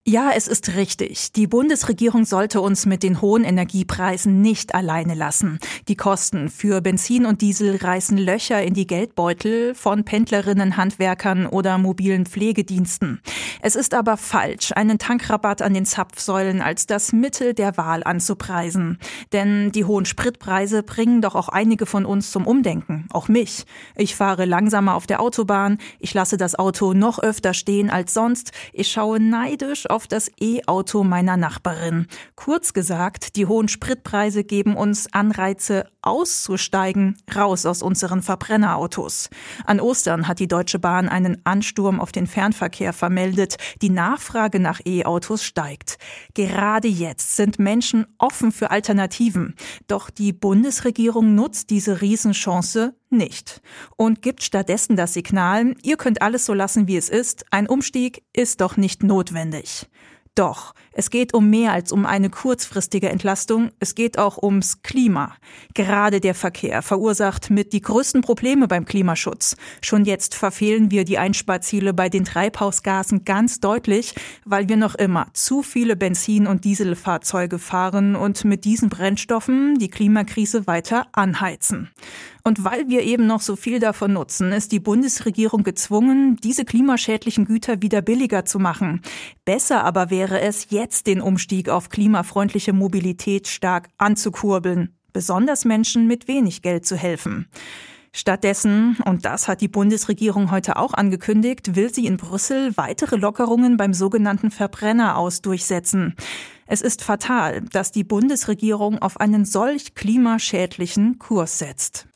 Kommentar